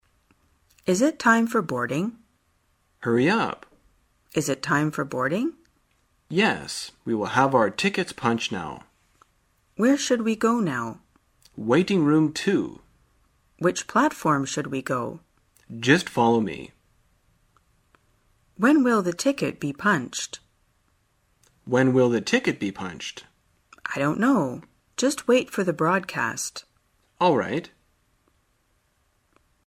在线英语听力室生活口语天天说 第97期:怎样检票的听力文件下载,《生活口语天天说》栏目将日常生活中最常用到的口语句型进行收集和重点讲解。真人发音配字幕帮助英语爱好者们练习听力并进行口语跟读。